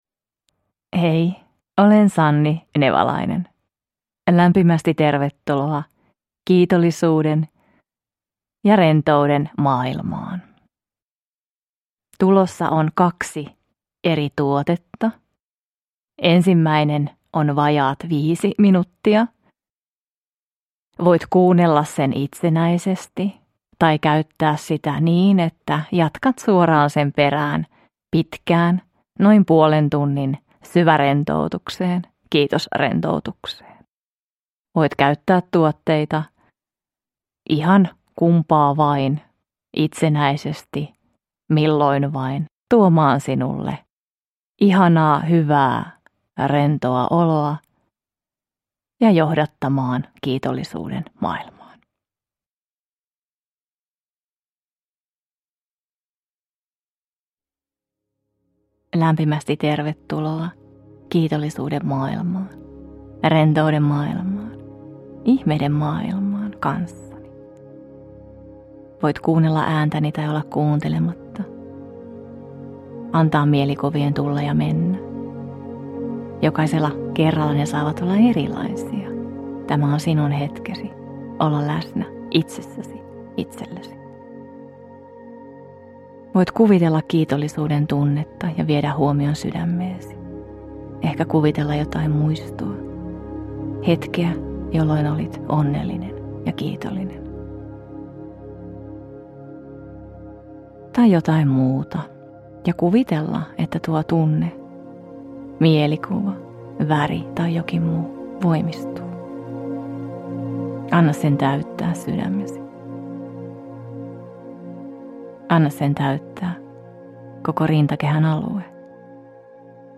Syvärentoutus Kiitos-meditaatio – Ljudbok – Laddas ner